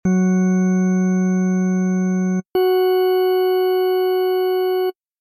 In many cases playing 2 notes next to each other sounds wrong, but as we are playing so high up this final overtone just adds more brightness, as long as it is not too loud.
You should now have a basic organ type tone, which you can play like any other instrument or combinator patch in Reason.